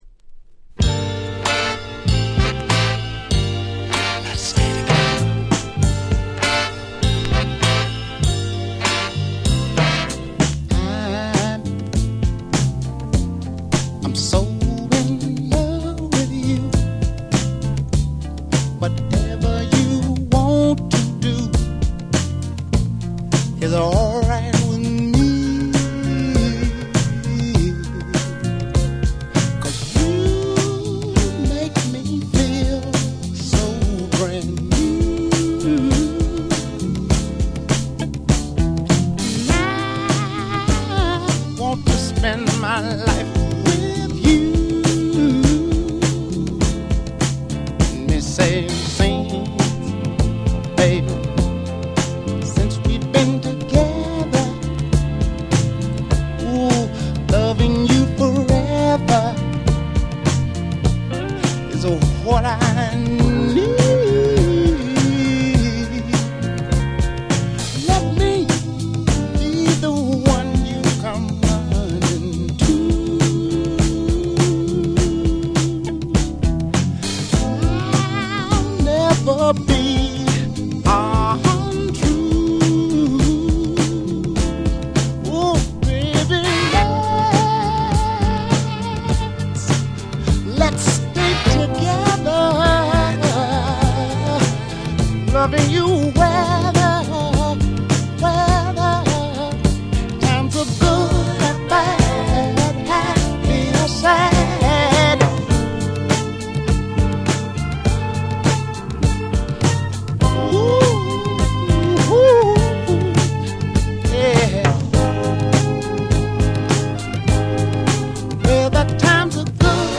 1972年リリースのSoul超名曲。